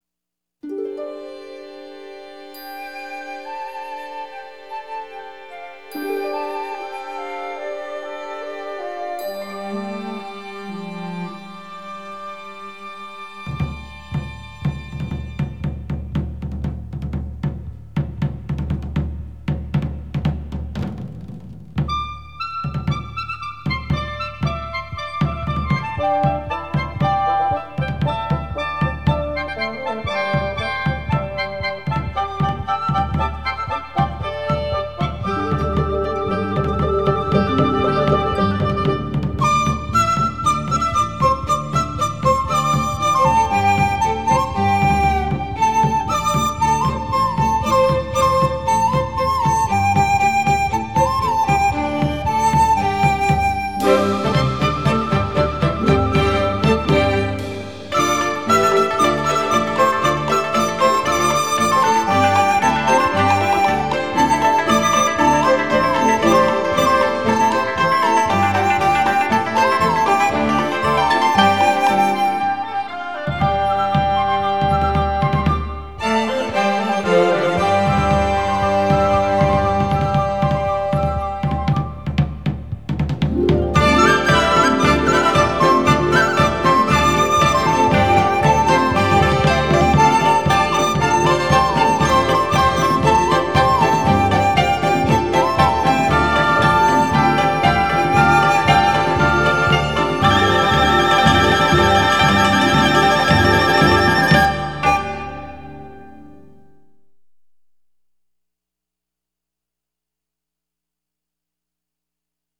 以西乐合奏团为主，加上国乐独奏乐器有二胡、笛子、琵琶、扬琴为辅，演奏的台湾乡土民谣
笛、扬琴
以弦乐加上定音鼓代表火车牵动，而主奏的笛子活泼地穿插其中。